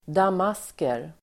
Uttal: [dam'as:ker]